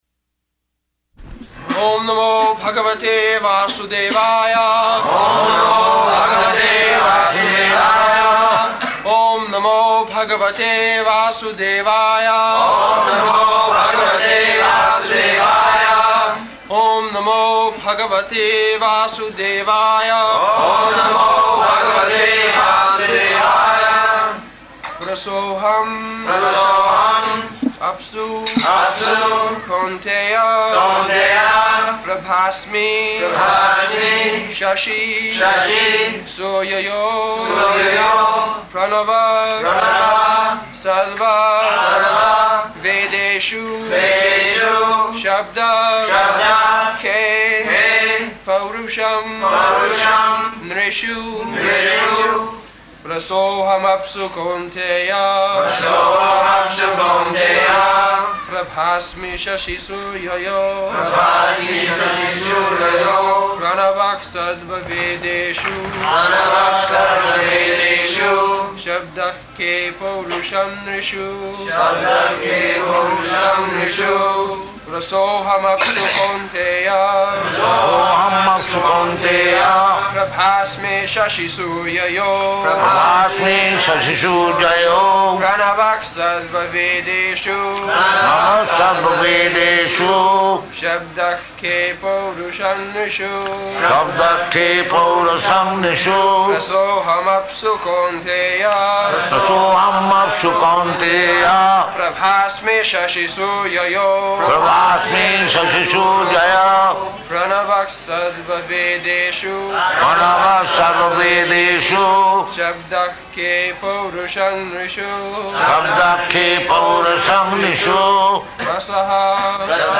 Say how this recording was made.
February 23rd 1974 Location: Bombay Audio file